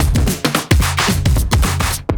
OTG_TripSwingMixA_110a.wav